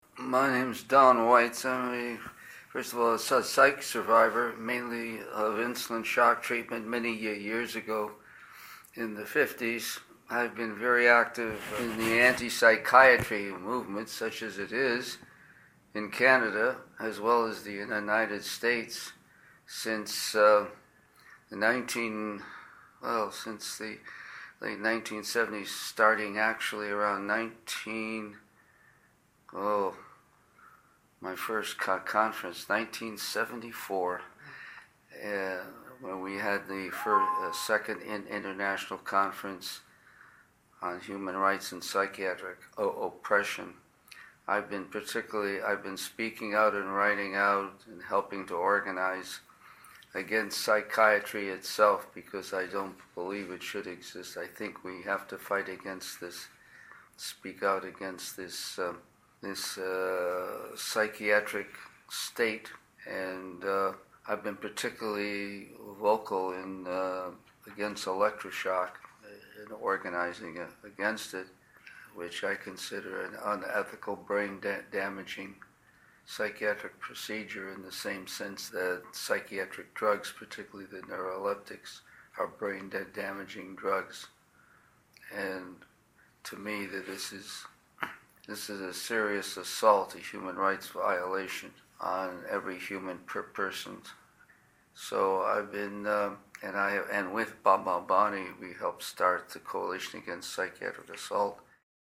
at the time of their oral history interview.